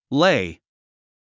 発音
léi　レイ
lay.mp3